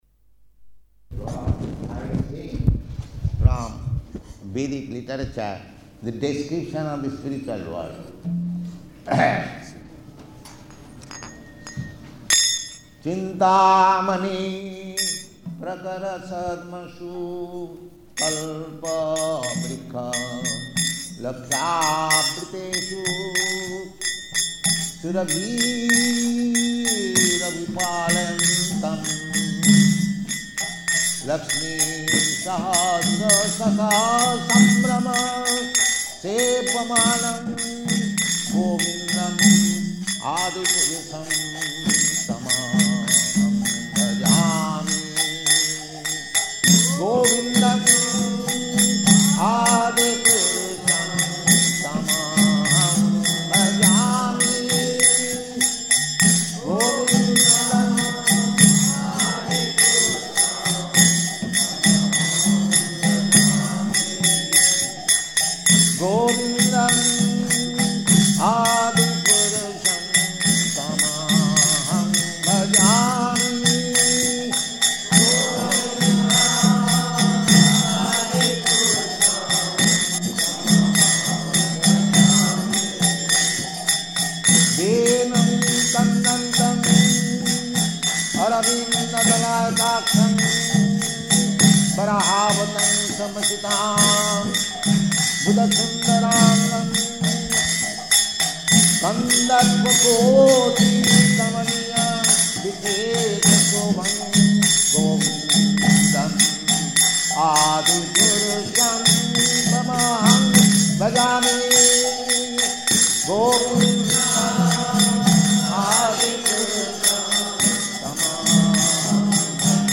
Śrī Brahma-saṁhitā 5.33, at Uppsala University, excerpt
Location: Stockholm
[chants Śrī Brahma-saṁhitā 5.29–38, excl. 6]